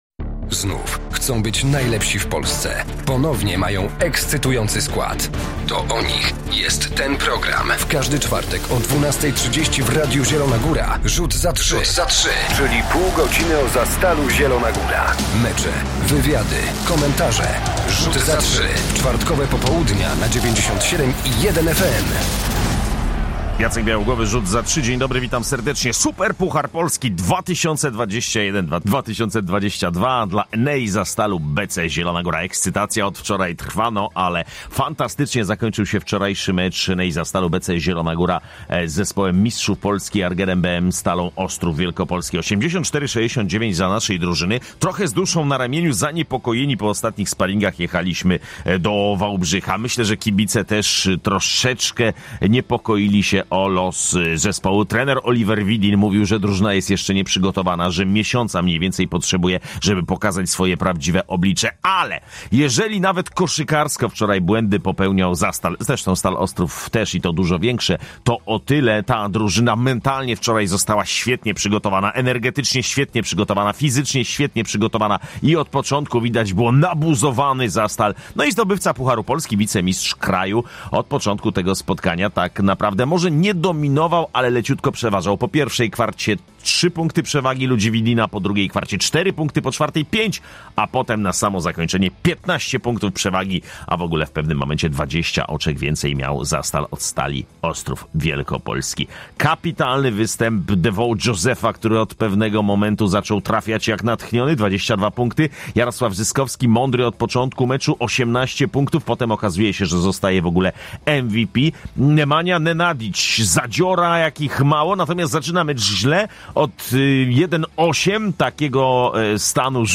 A w programie słuchamy bohaterów wałbrzyskiego meczu – trenerów, zawodników i kibiców: